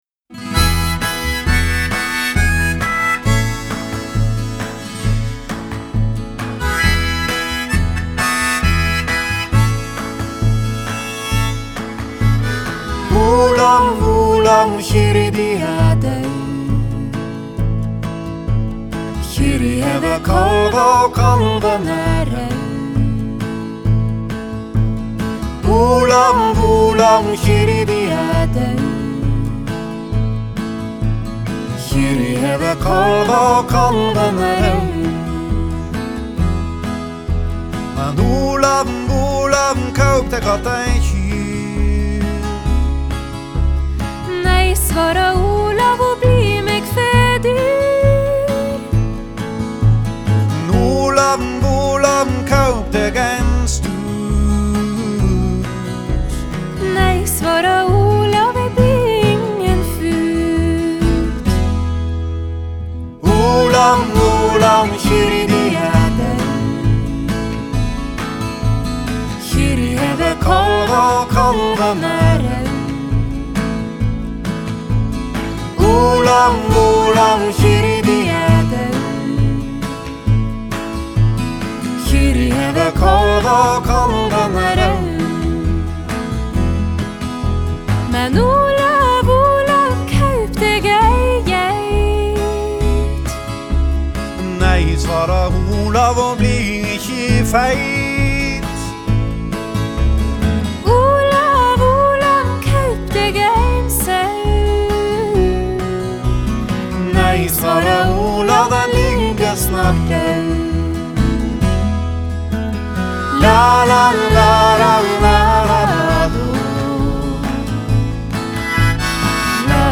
Genre: Folk-Rock, Folk-Pop, Folk
vocals, Guitar, banjo, harmonica, percussion, accordion